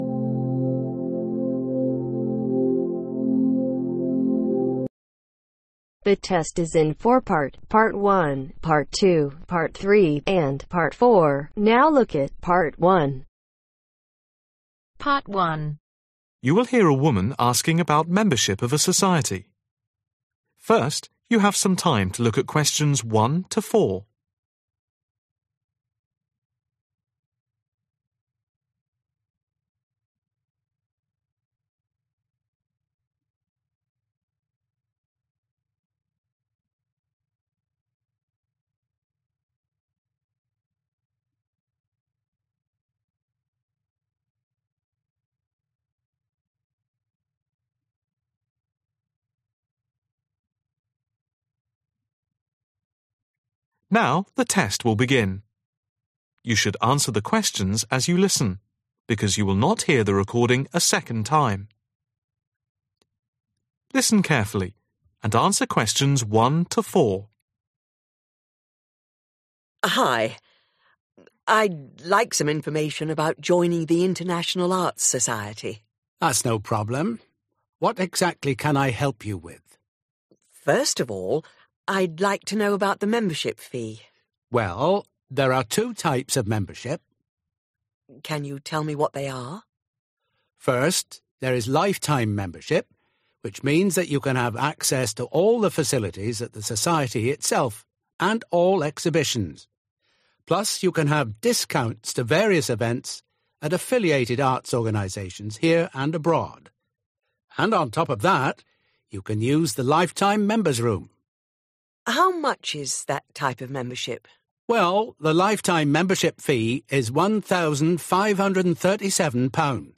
Listening module in ielts with answer